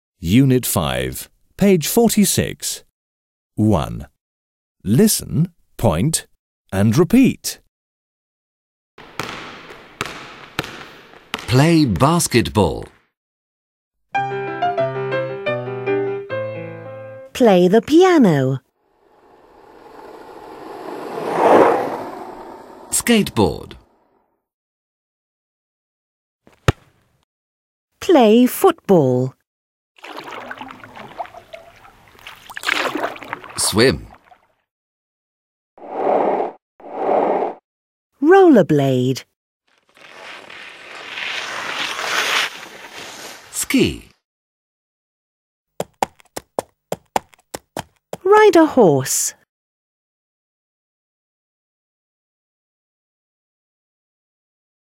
Pupil´s book 46 / 1 – poslechněte si jednotlivé výrazy (příloha  - nahrávka 46 / 1), ukazujte na obrázky a vyslovujte.